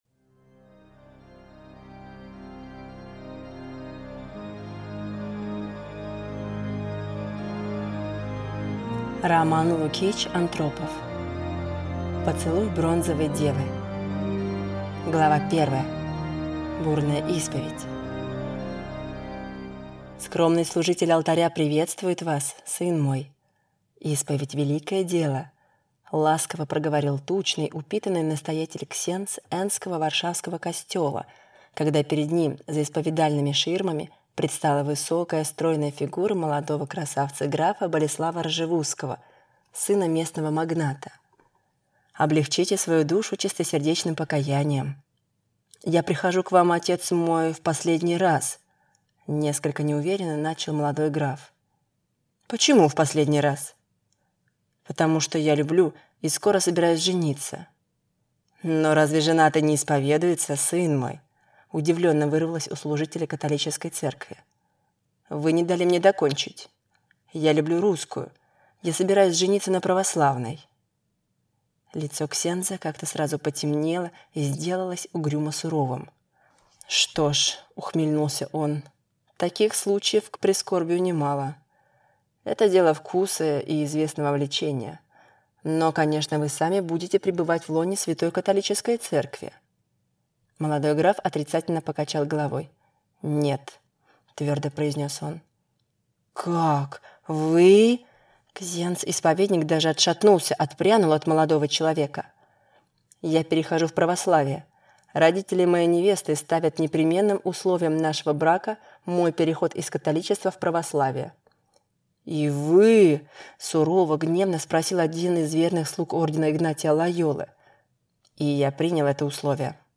Аудиокнига Поцелуй бронзовой девы | Библиотека аудиокниг